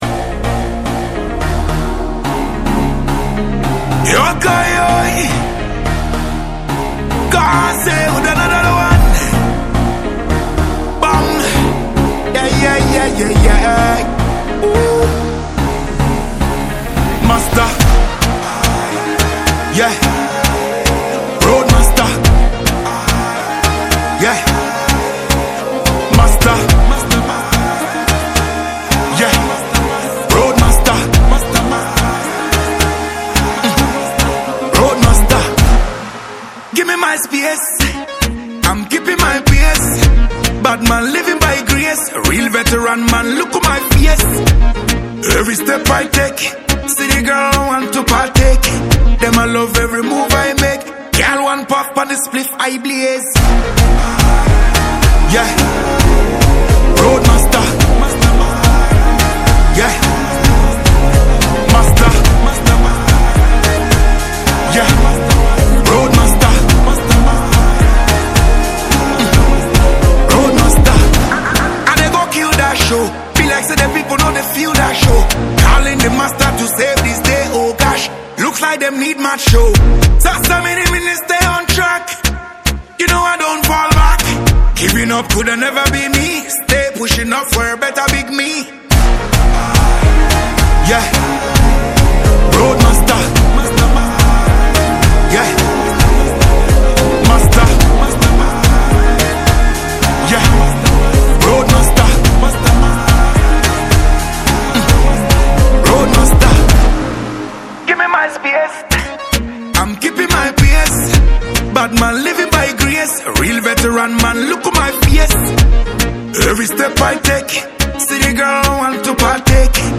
• Genre: Dancehall / Afro-reggae